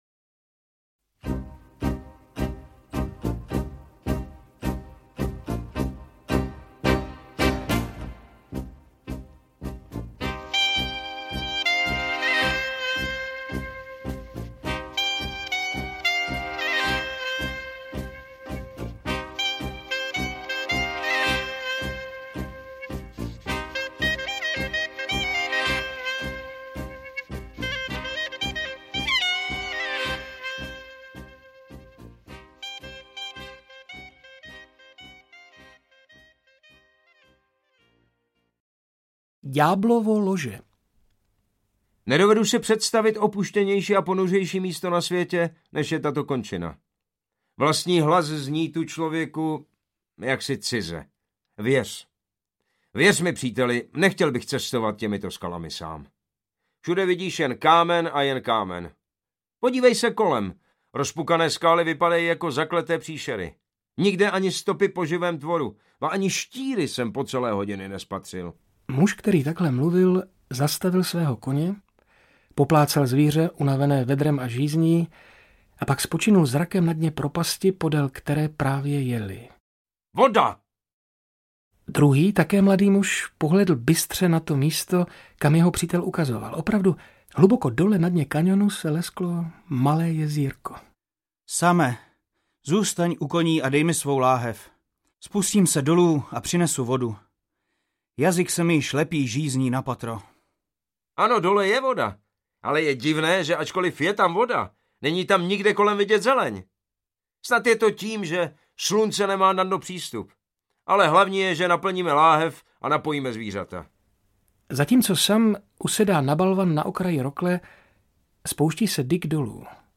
Příběhy odvahy a dobrodružství z časopisu Mladý hlasatel - Otakar Batlička - Audiokniha